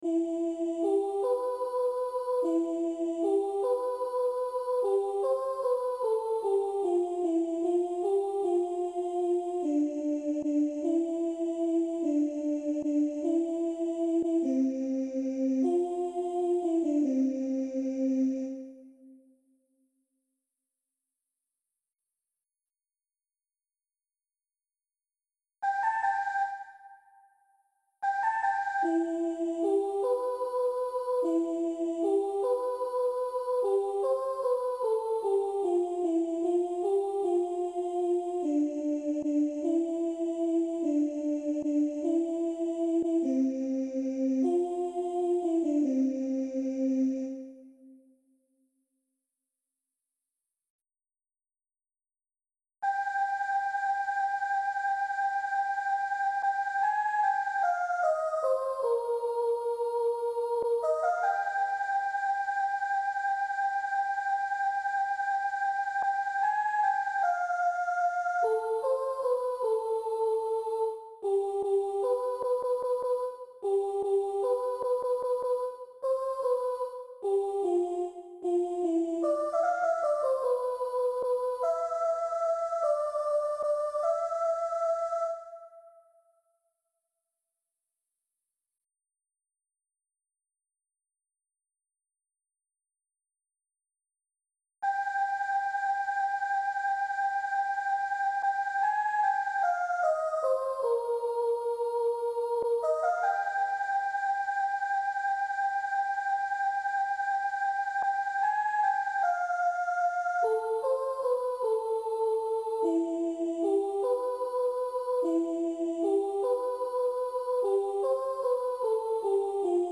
we_ll_stand_together  We’ll stand togeth (sopraan)